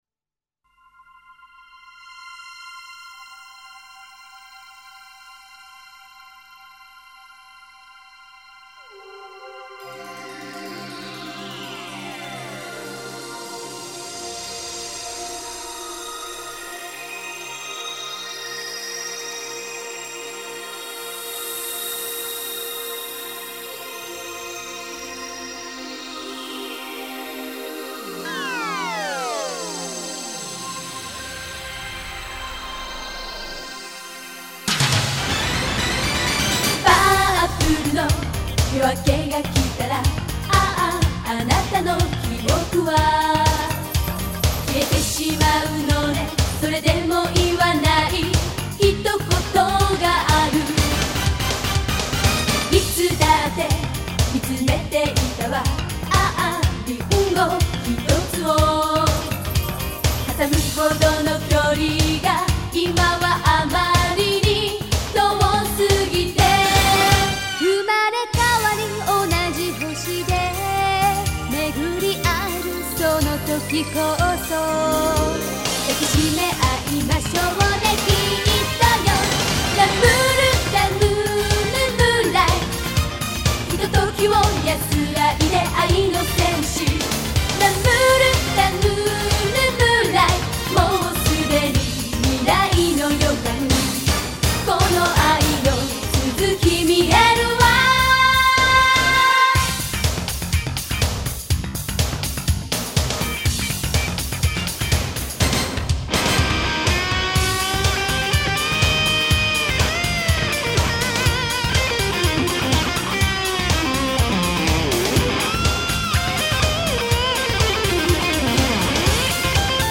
Звучала в мюзикле 1995